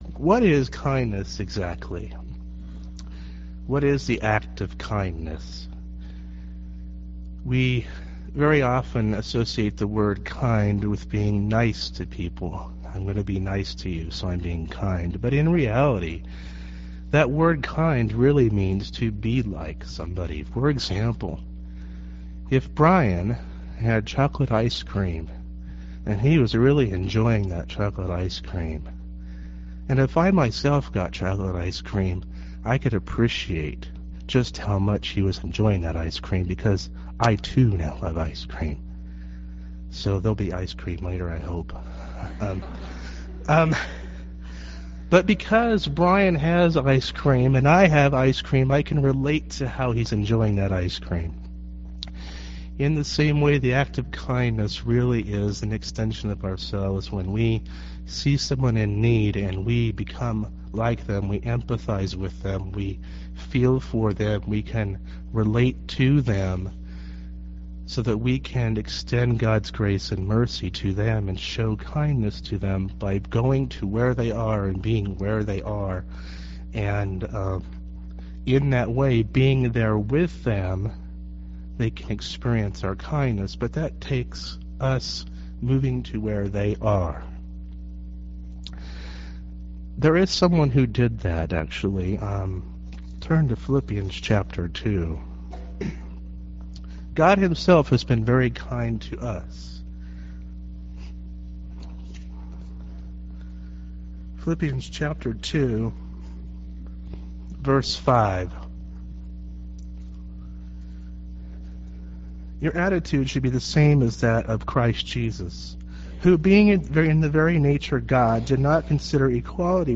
while fighting sickness and congestion!